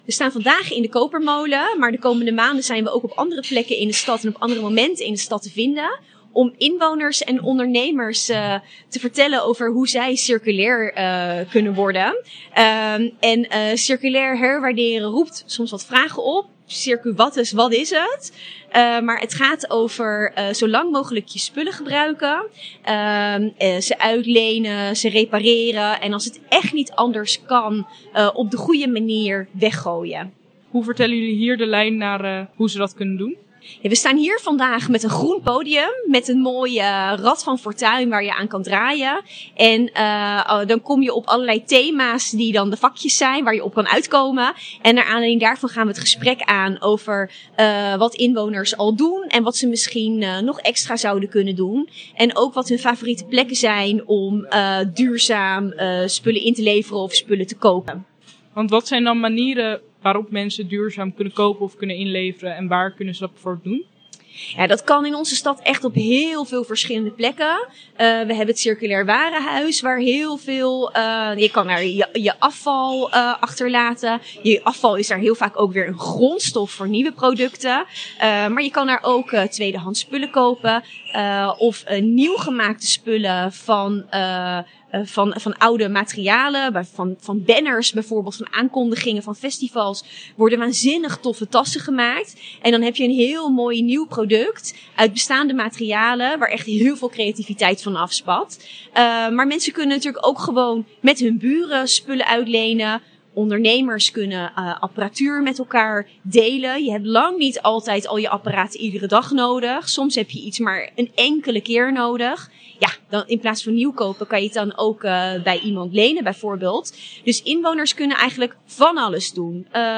Wethouder Prescillia van Noort over circulair herwaarderen in Leiden: